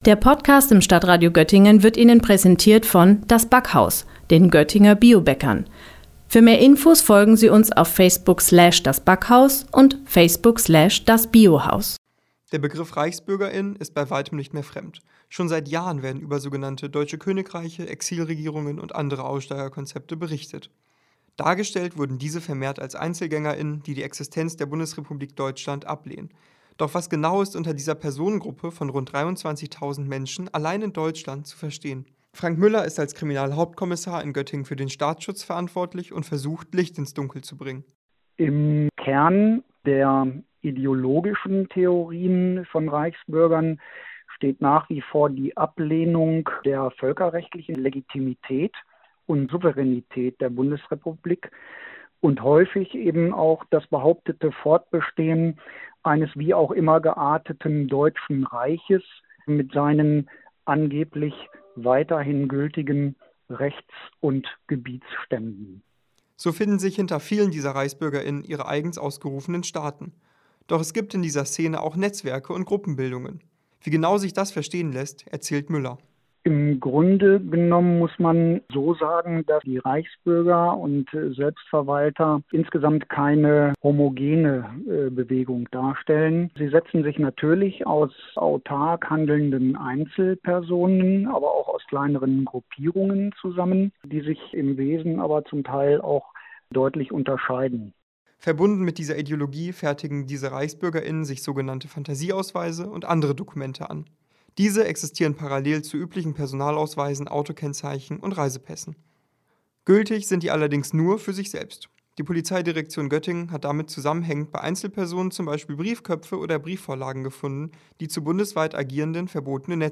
Beiträge > Reichsbürger*innen in Südniedersachsen – Ein Gespräch mit dem Staatsschutz - StadtRadio Göttingen